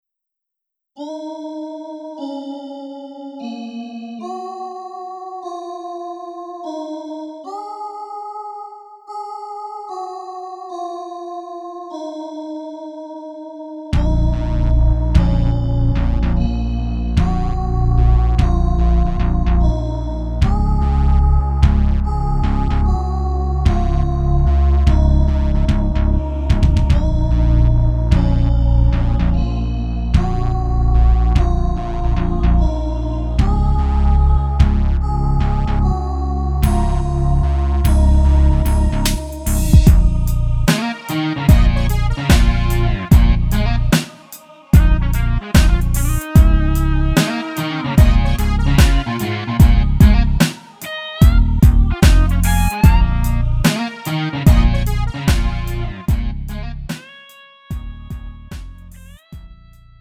음정 -1키 2:18
장르 구분 Lite MR